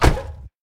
car-door-close-1.ogg